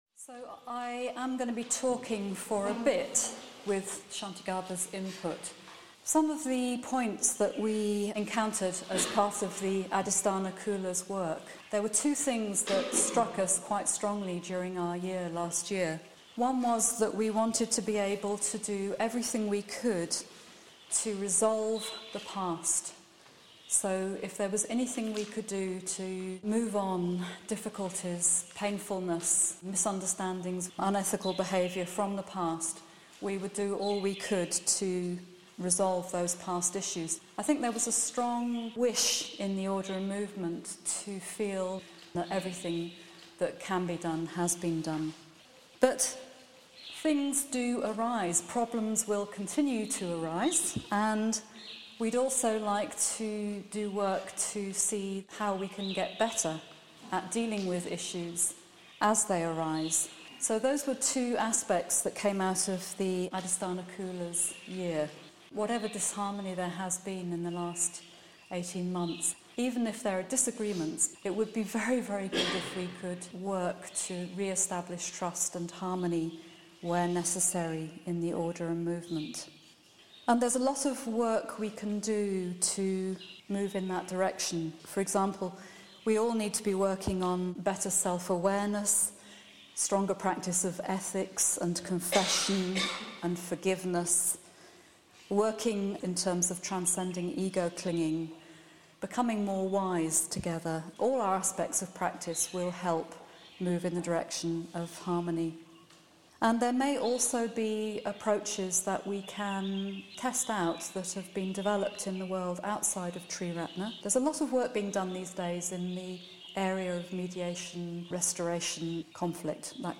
Exploring its principles and its methodology, this short talk also provides a helpful overview of why the Restorative approach is considered such a good fit for a Buddhist community. Recorded in India, spring 2018.